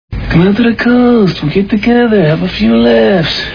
Die Hard Movie Sound Bites